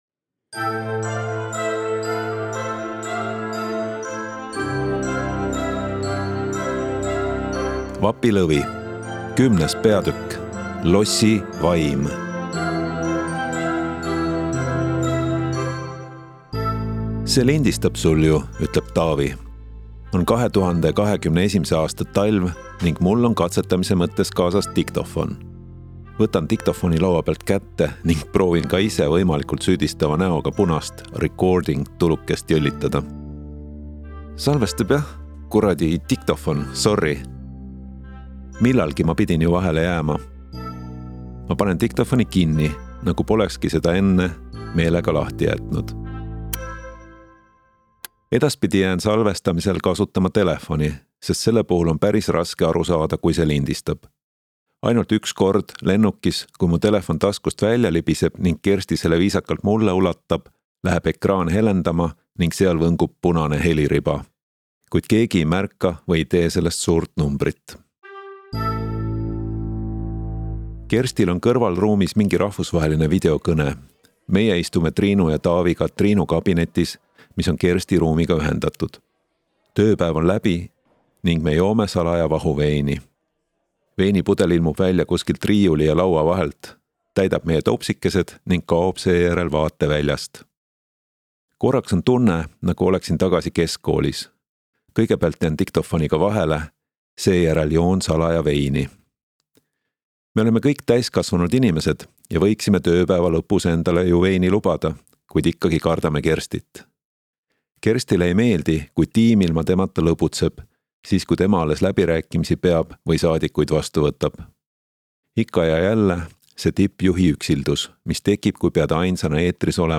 Osta kõik peatükid audioraamat e-raamat 11,99 € Telli raamat audioraamat e-raamat paberraamat Järgmine lugu